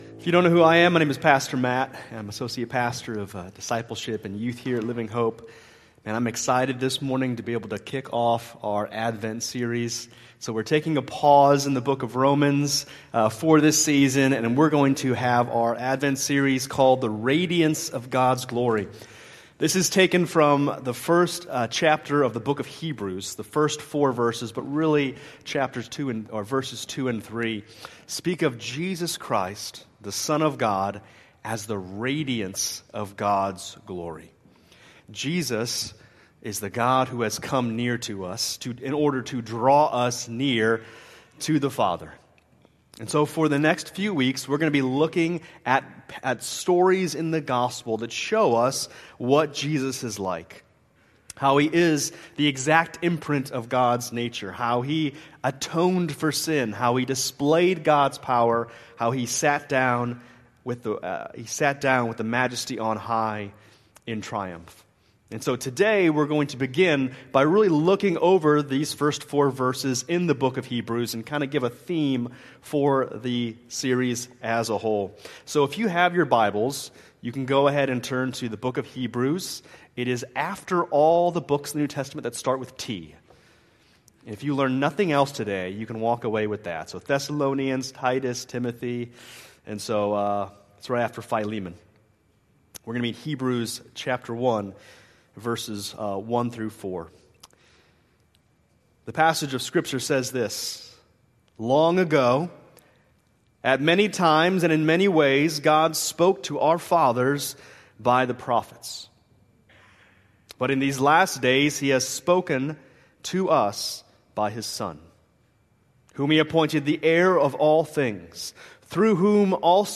December 7, 2005 Worship Service Order of Service: